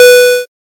safe-4.ogg.mp3